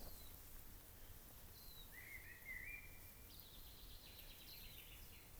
A silvereye | tauhou, a eurasian blackbird, and a common chaffinch Direct link to audio file
silvereye_eurasianblackbird_commonchaffinch.wav